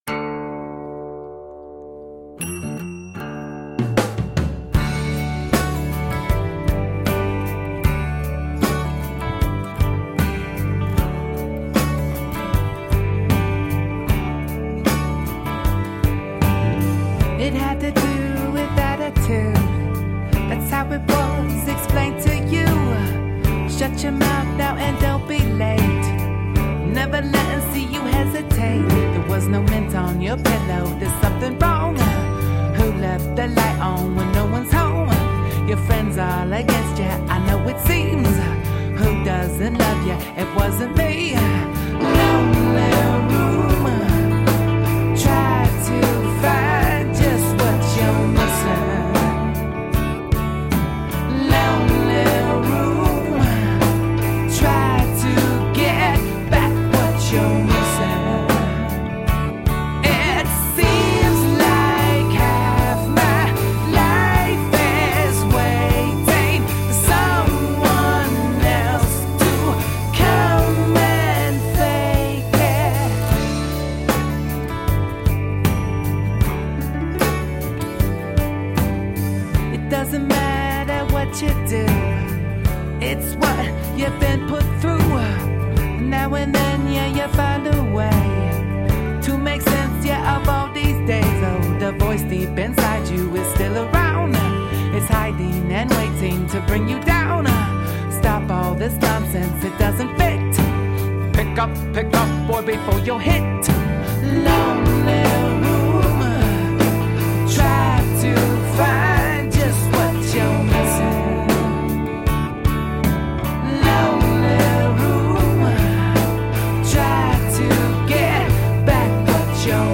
A swirling vortex of rock, funk, rap,and alternative.
Vox
Guitars, Bass
Keys
Drums and Percussion
Tagged as: Alt Rock, Rock, Pop